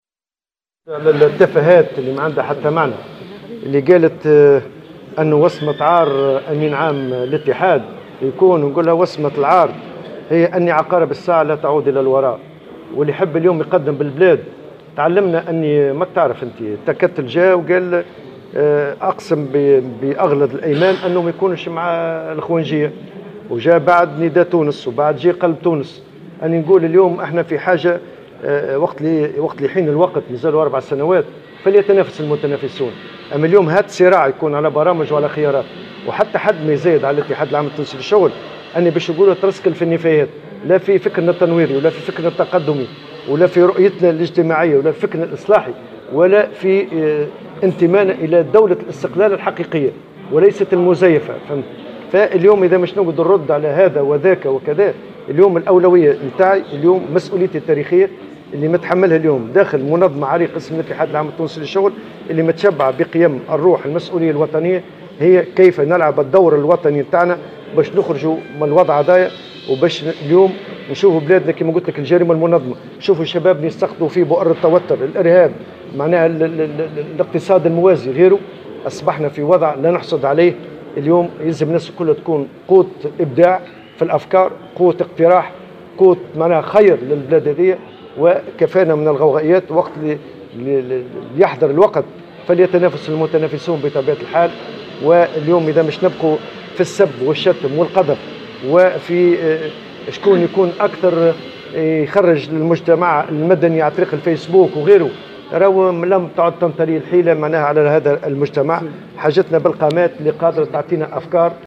وصف الأمين العام للاتحاد العام التونسي للشغل نور الدين الطبوبي، في تصريح للجوهرة أف أم، تصريحات رئيسة الحزب الدستوري الحر عبير موسي، بـ"التفاهات"، قائلا إن أولويات المنظمة الشغيلة اليوم هي لعب دوره الوطني للخروج من الوضع الصعب الذي تمر به البلاد.